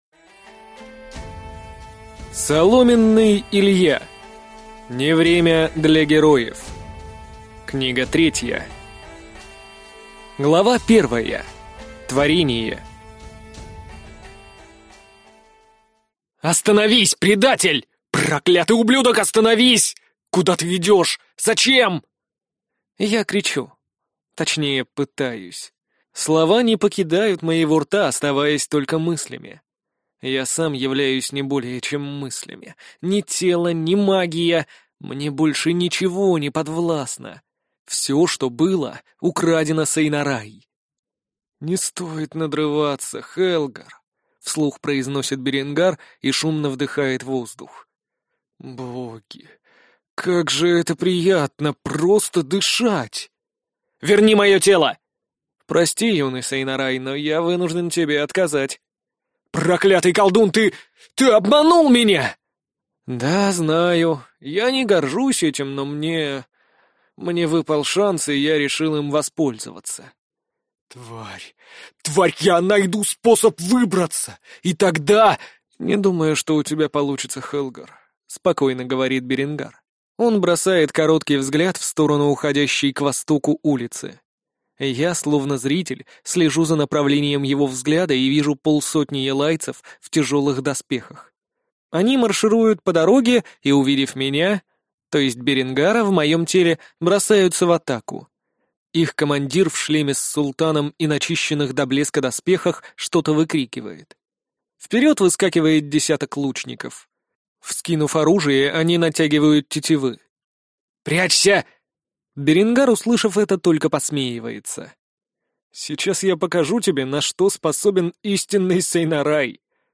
Аудиокнига Не время для героев. Книга 3 | Библиотека аудиокниг